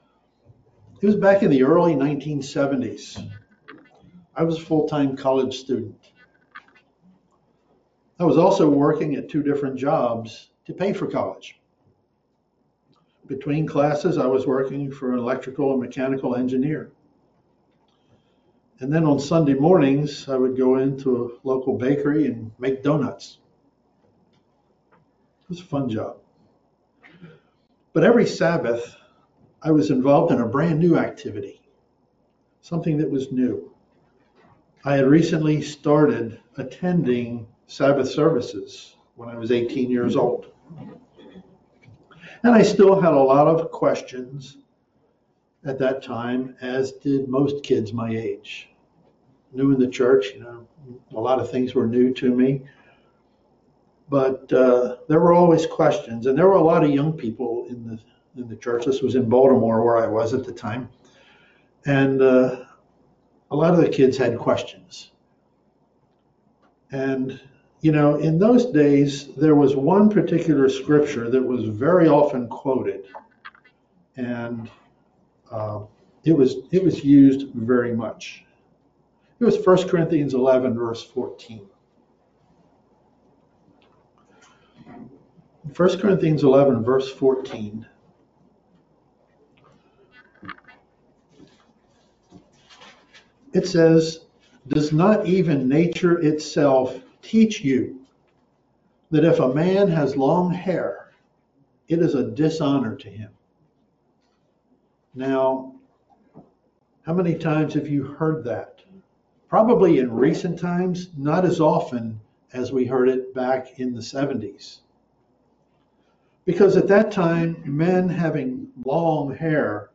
Sermons
Given in Philadelphia, PA